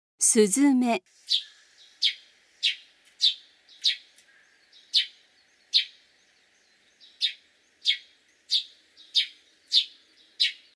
スズメ
【鳴き声】普段は「チュン」「チュリリ」などと鳴き、オスは繁殖期に「チュリ チョ チョン」と繰り返してさえずる。
スズメの鳴き声（音楽：177KB）